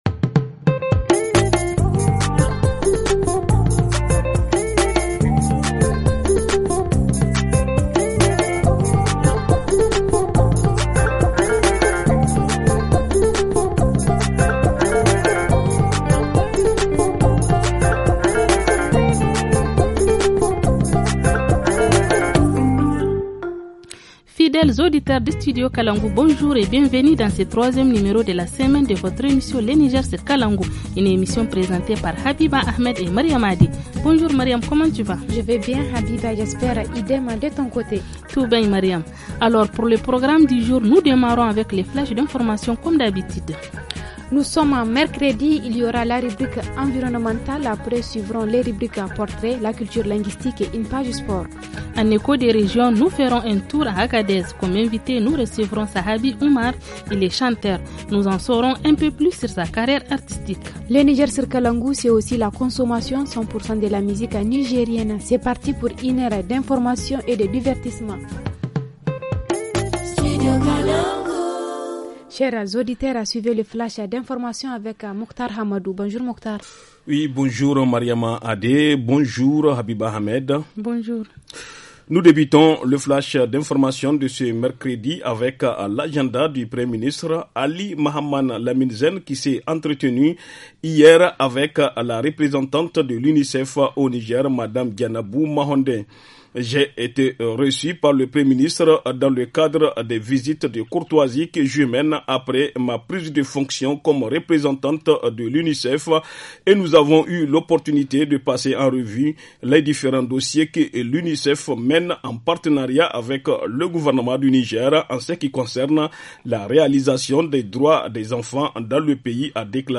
Dans la rubrique hebdomadaire, focus sur la rareté du bois de chauffe à Arlit. En reportage région, une importante saisie de drogue dans la région d’Arlit.